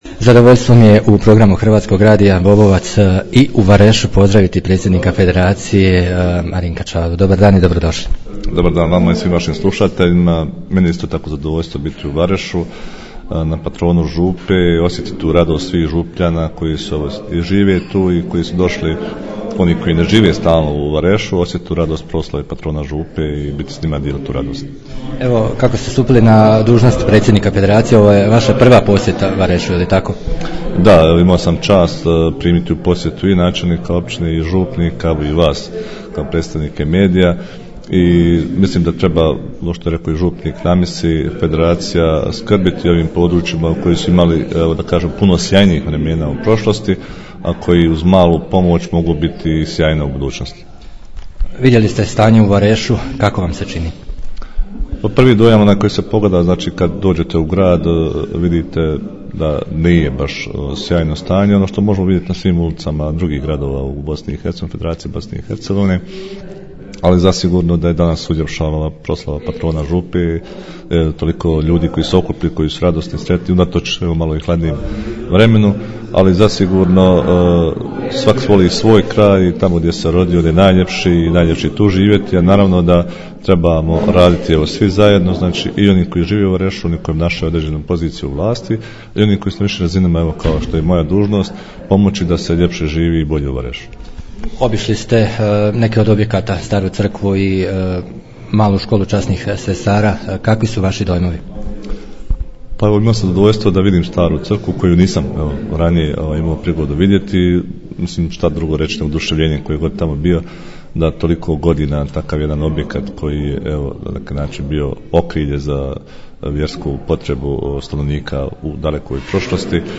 Razgovor s predsjednikom FBiH
Povodom patrona župe Vareš, u posjetii je bio predsjednik Federacije Bosne i Hercegovine Marinko Čavara.